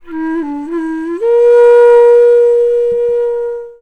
FLUTE-B04 -L.wav